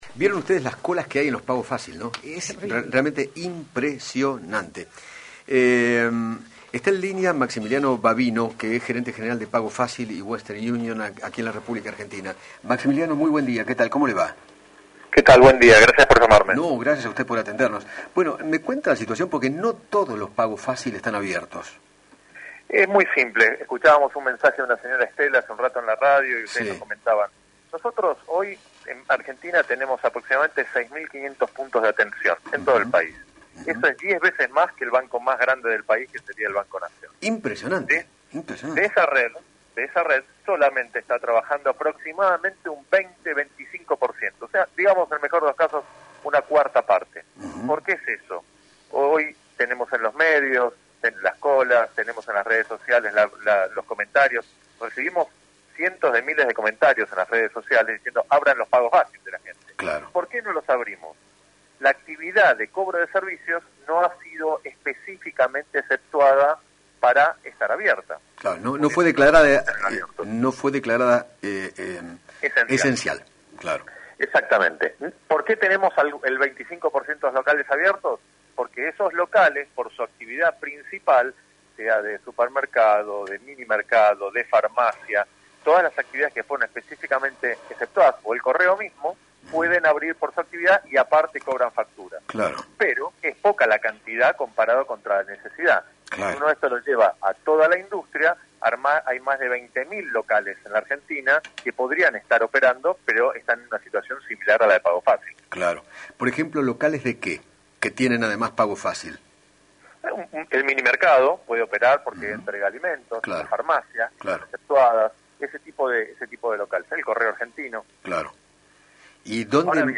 dialogó con Eduardo Feinmann sobre las pocas sucursales que están operando porque no fue declarado como actividad esencial y sostuvo que “necesitamos dar una solución a las 20 millones de personas que pagan sus facturas todos los meses”.